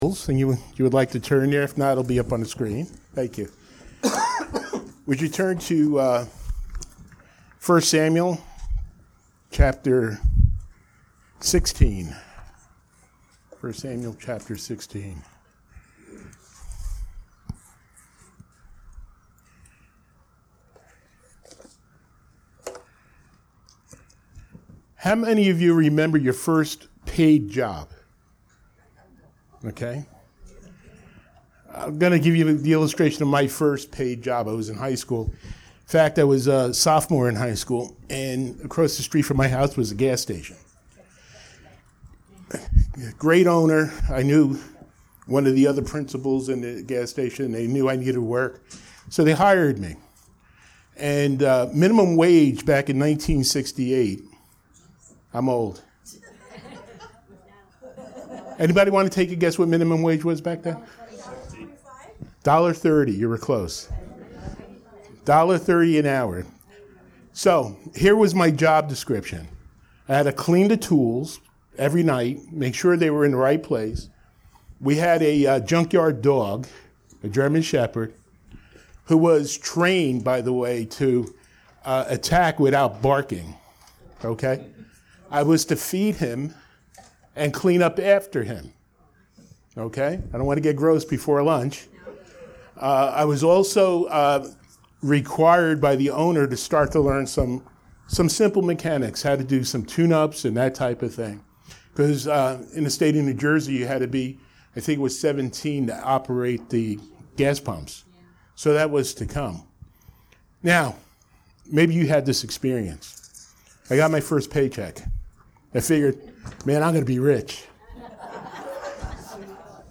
Series: Sunday Morning Worship